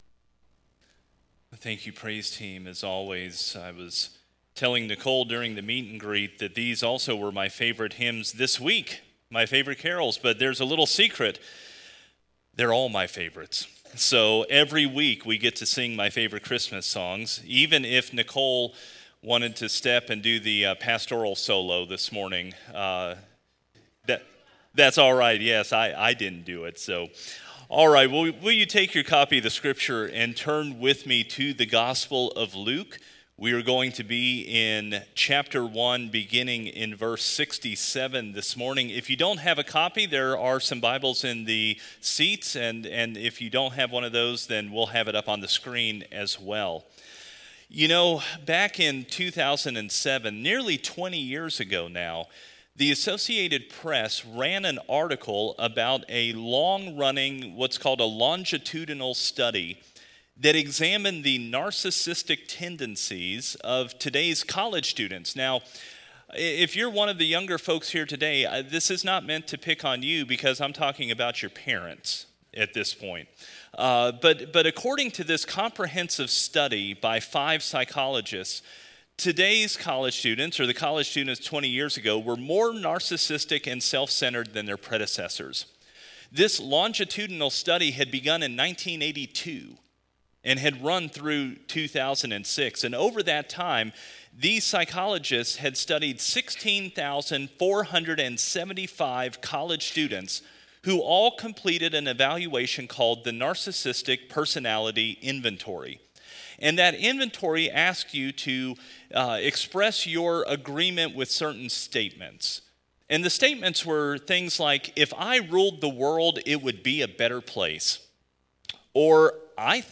Sermons by Faith Baptist Church